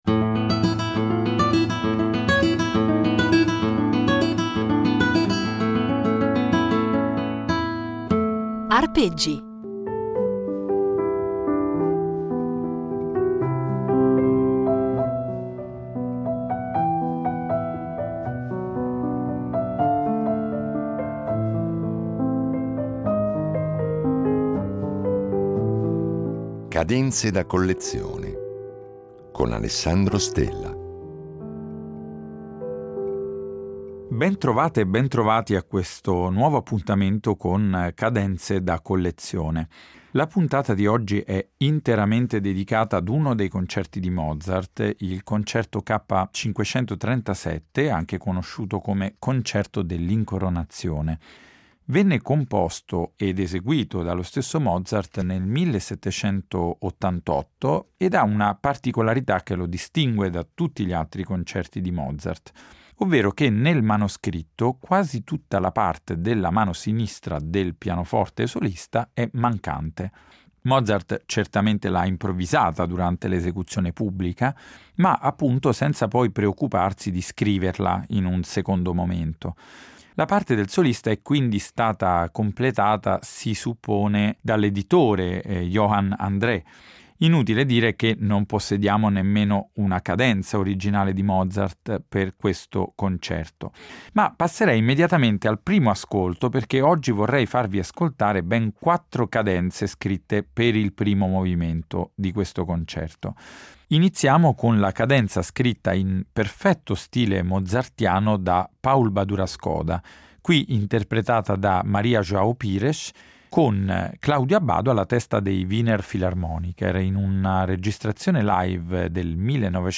Da quelle scritte dagli autori, a volte in due distinte versioni, a quelle firmate o improvvisate dagli interpreti, dal concerto alla sonata perché come vedremo la pratica della cadenza compare anche nella musica da camera, una bella carrellata di esempi memorabili e significativi commentati per noi da un appassionato specialista della materia.